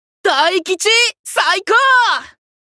文件 文件历史 文件用途 全域文件用途 Gotoutoushirou_Omikuji２.mp3 （MP3音频文件，总共长2.7秒，码率64 kbps，文件大小：21 KB） 后藤藤四郎新年神签台词2 文件历史 点击某个日期/时间查看对应时刻的文件。